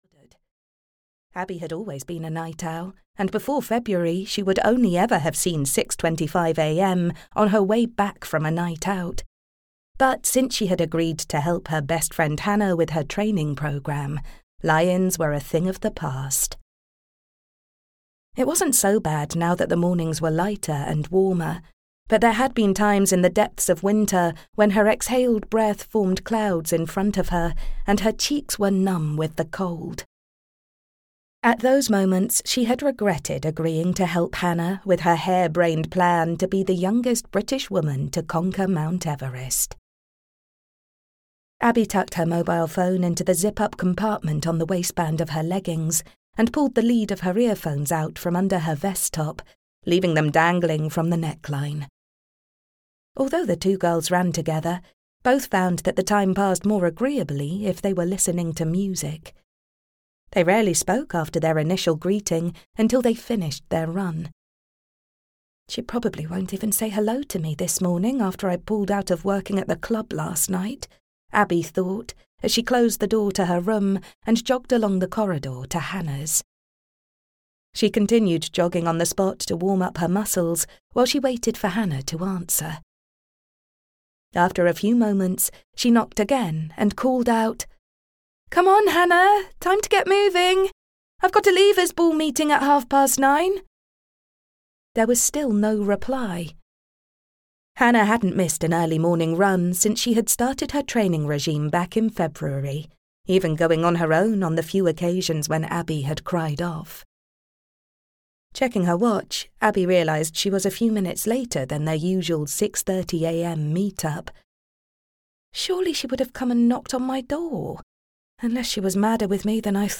Why She Died (EN) audiokniha
Ukázka z knihy